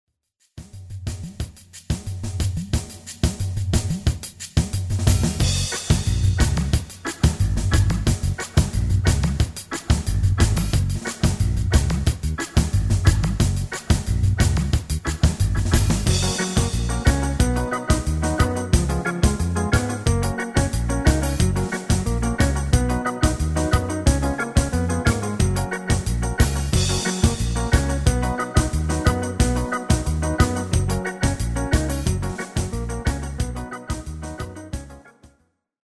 Demo/Koop midifile
Genre: Reggae / Latin / Salsa
- Géén tekst
- Géén vocal harmony tracks
Demo = Demo midifile